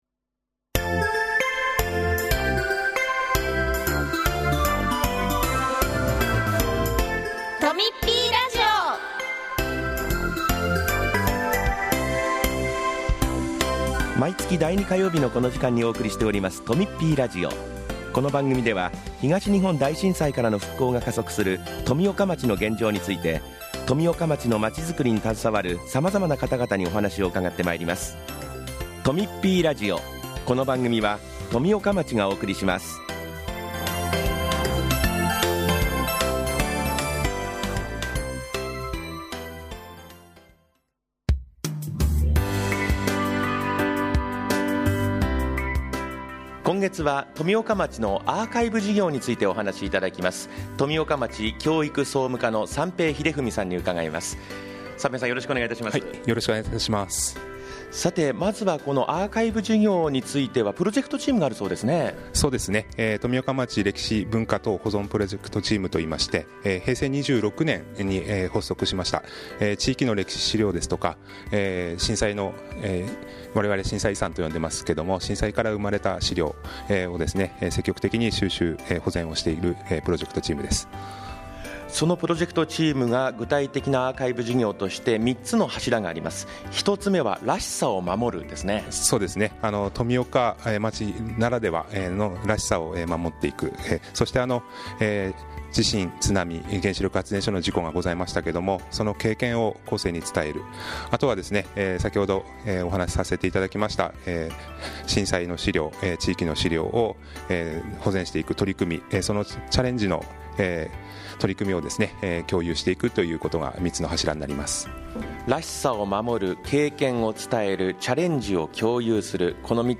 今回は、教育総務課の職員による「アーカイブ事業」のインタビューです。その他、町からのお知らせもあります。